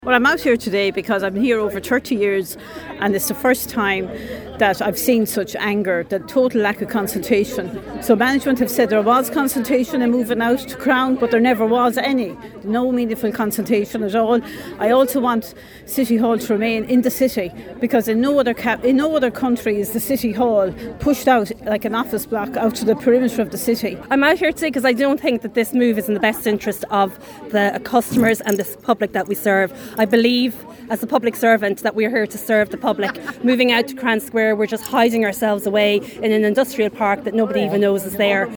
These protestors at City Hall today gave us their opinion on the the situation.